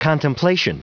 Prononciation du mot contemplation en anglais (fichier audio)
Prononciation du mot : contemplation